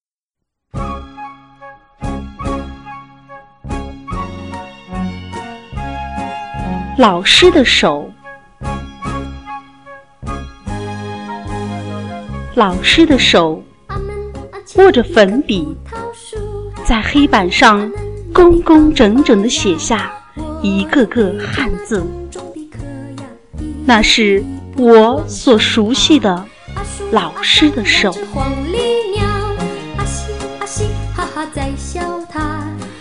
本节学习录音、降噪、合成、导出的基础知识；
3、配音伴奏
3）在出来的对话框中，将“幅度增加”设为-5，正的是放大音量，负数是降低音量，这里我们降低伴奏的音量，加大音量时打勾下面的“允许破音”；